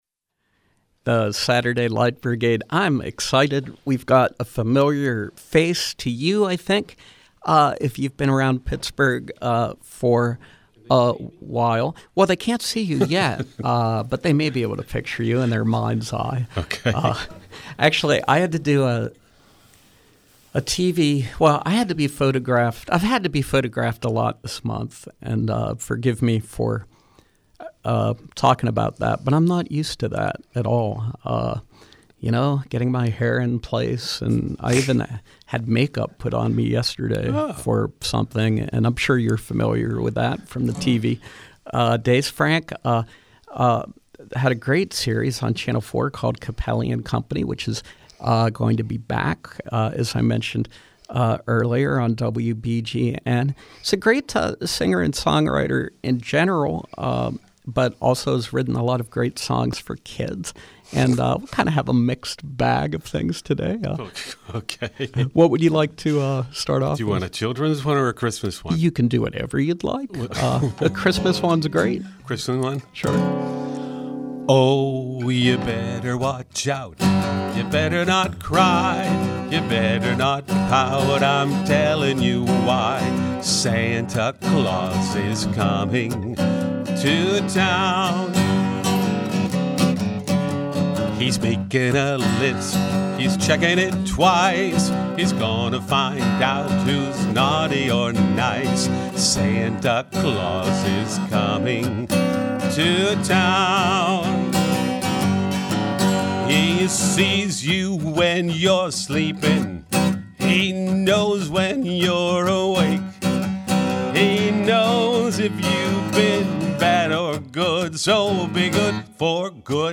Original songs for children and adults